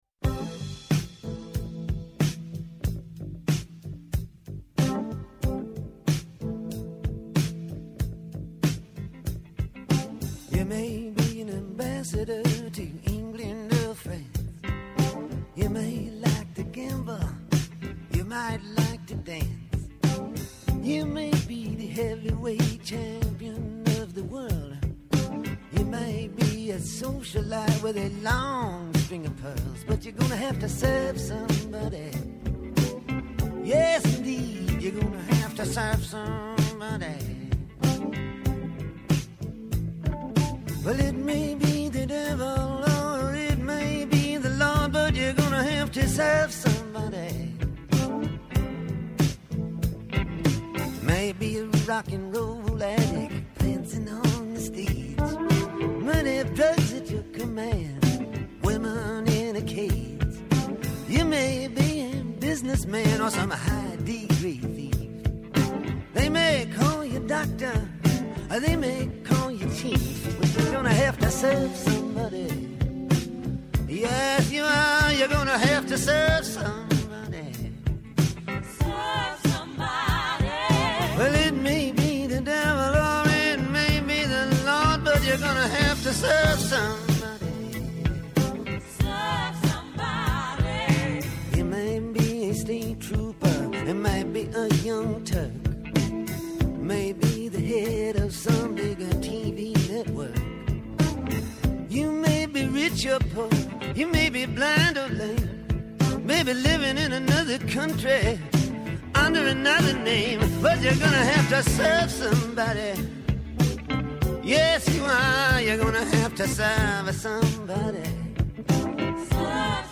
-Η Δρ. Διονυσία-Θεοδώρα Αυγερινοπούλου, βουλευτής ΝΔ και Ειδική Απεσταλμένη για τους Ωκεανούς, με αφορμή την Παγκόσμια Συνδιάσκεψη για τους Ωκεανούς (9th Our Ocean Conference) που θα πραγματοποιηθεί στην Αθήνα από τις 15 έως τις 17 Απριλίου
Άνθρωποι της επιστήμης, της ακαδημαϊκής κοινότητας, πολιτικοί, ευρωβουλευτές, εκπρόσωποι Μη Κυβερνητικών Οργανώσεων και της Κοινωνίας των Πολιτών συζητούν για όλα τα τρέχοντα και διηνεκή ζητήματα που απασχολούν τη ζωή όλων μας από την Ελλάδα και την Ευρώπη μέχρι την άκρη του κόσμου.